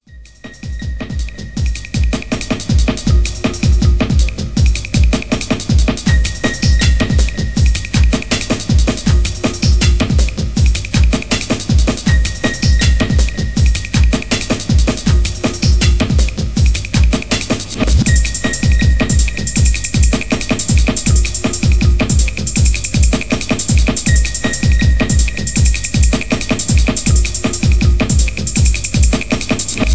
Tags: dnb